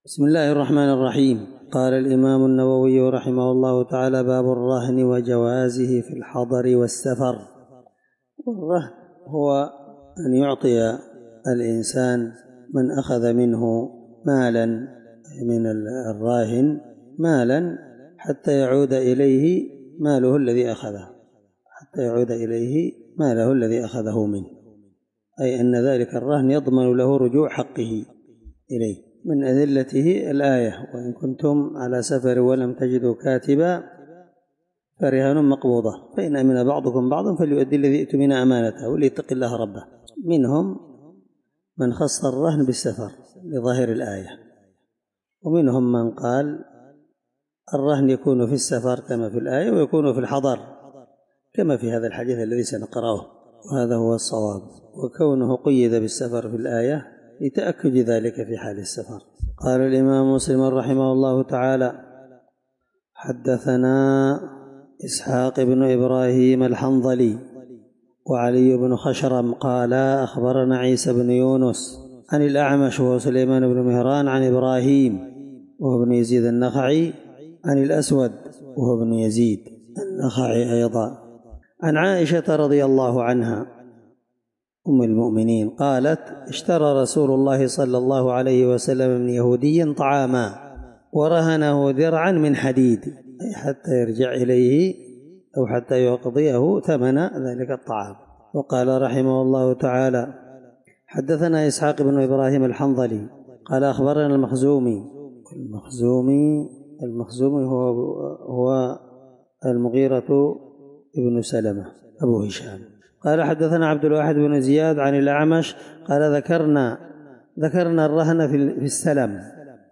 الدرس29من شرح كتاب المساقاة حديث رقم(1603) من صحيح مسلم